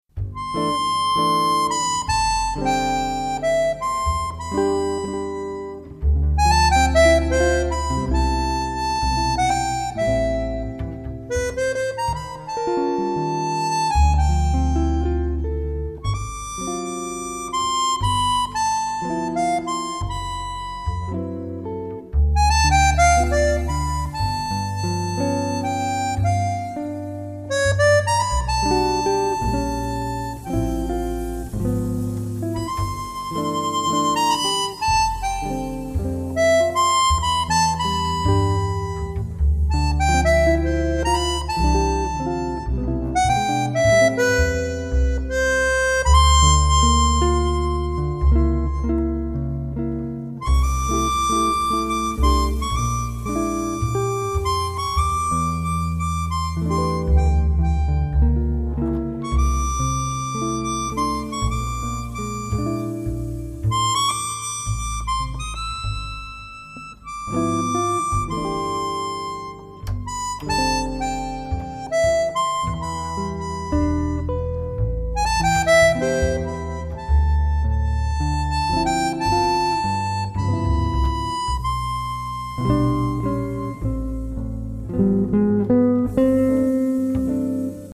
VIBRANDONEON